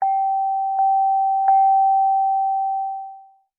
Library Bell Sound Effect Free Download
Library Bell